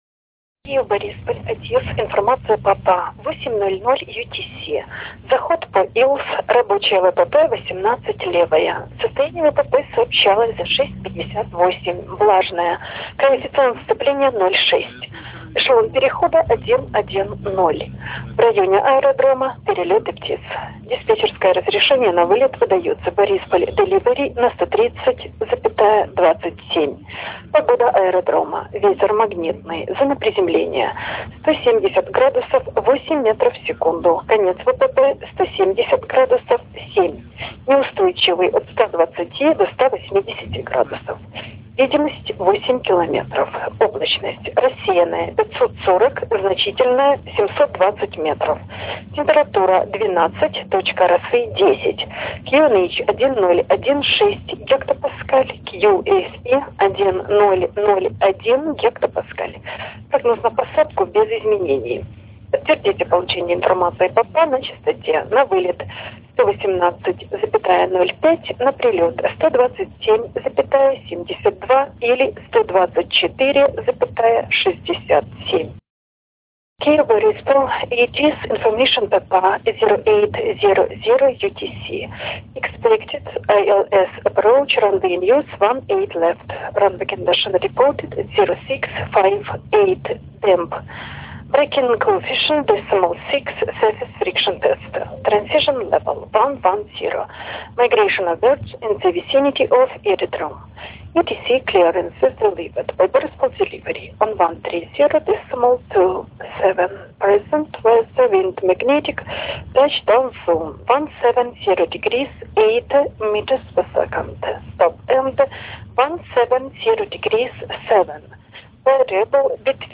Начало » Записи » Записи радиопереговоров - авиация
ATIS аэропорта Киев-Борисполь на русском и английском языке.
Частота 126,700 МГц.